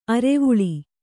♪ arevuḷi